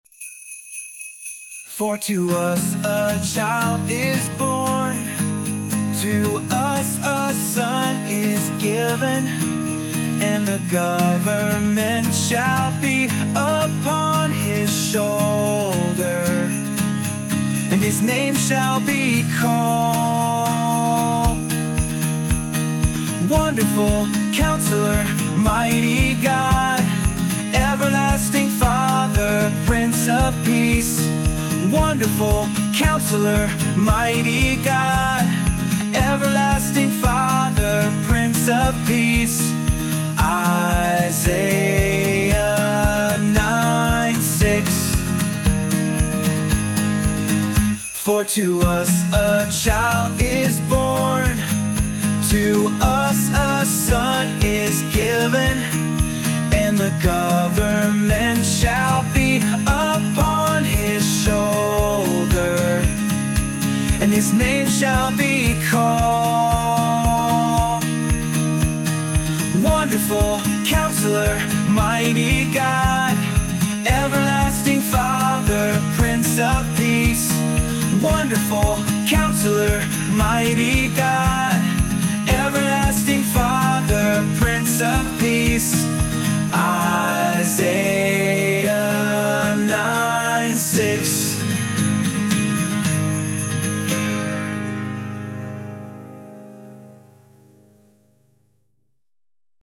Memory Verse Song
mem-verse-song-dec-2025.mp3